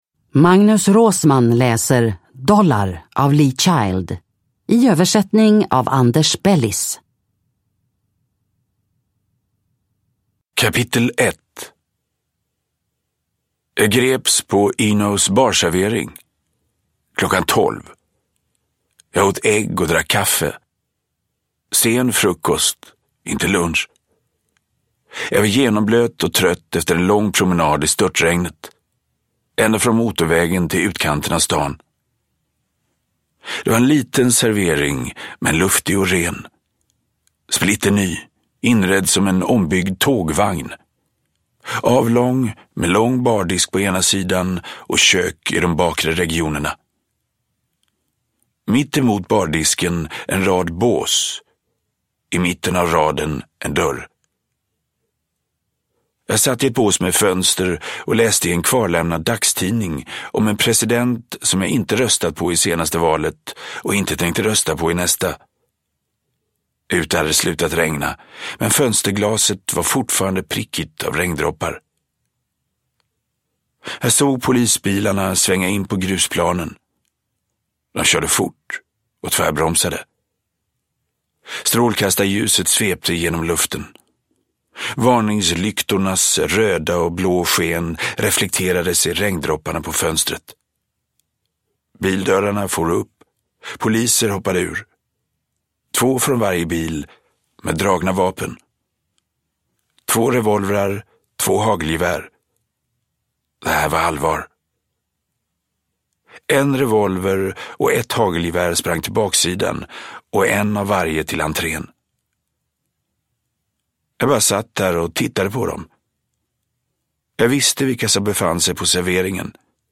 Dollar (ljudbok) av Lee Child